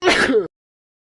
Sneeze 2
Sneeze 2.mp3